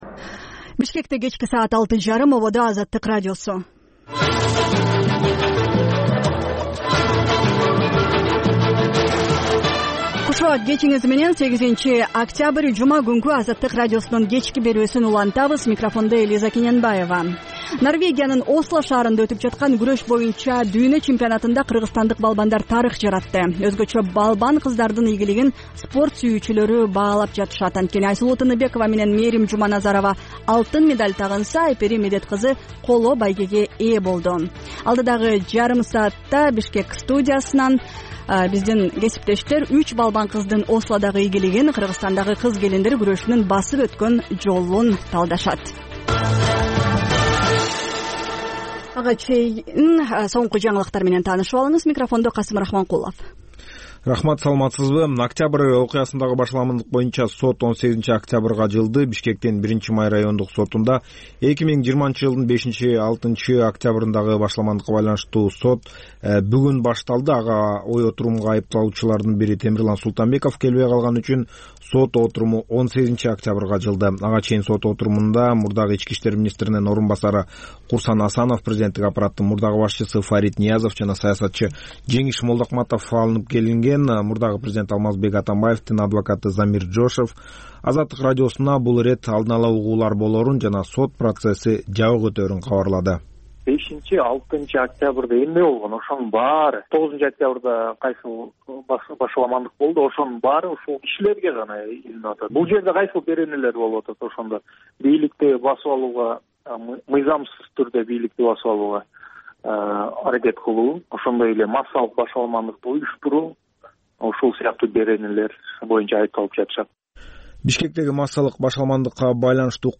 Бул үналгы берүү ар күнү Бишкек убакыты боюнча саат 18:30дан 19:00гө чейин обого түз чыгат.